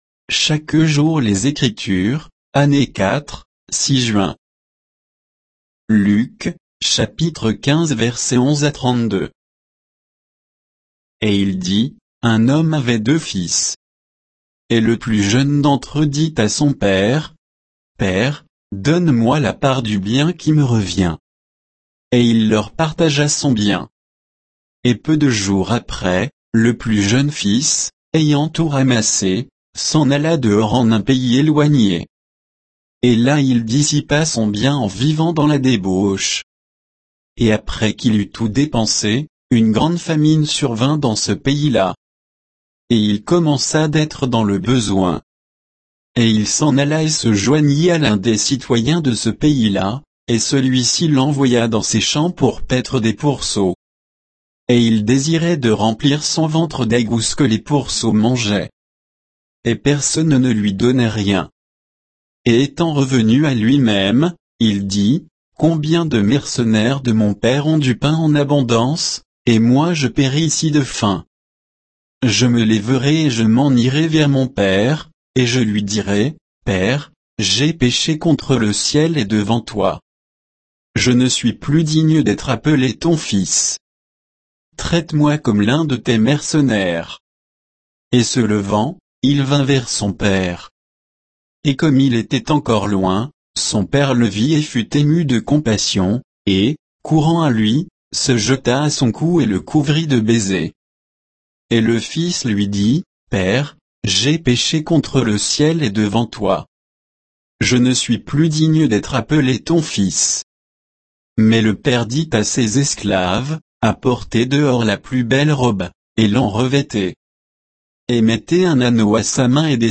Méditation quoditienne de Chaque jour les Écritures sur Luc 15, 11 à 32